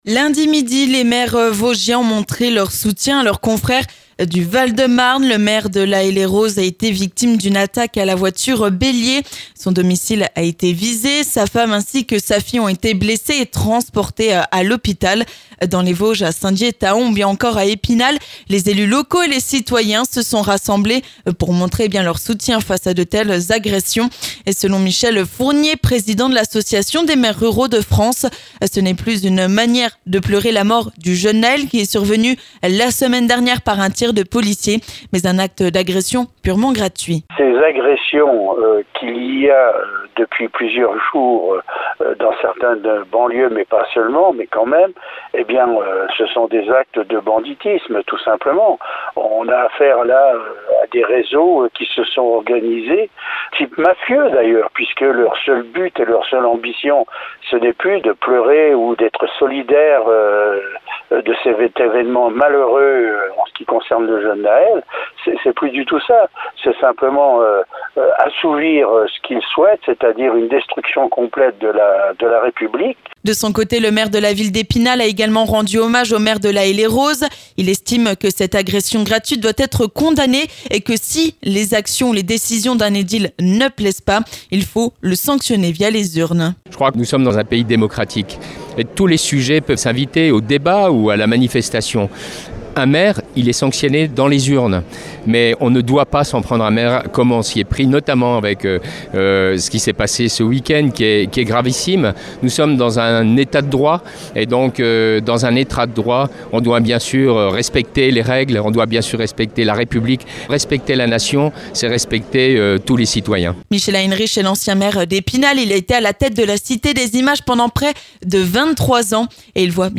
On en parle avec Michel Fournier, le président de l'association des Maires Ruraux de France et maire de Les Voivres, Patrick Nardin, maire d'Epinal et Michel Heinrich, ancien maire d'Epinal et président de la Communauté d'Agglomérations d'Epinal.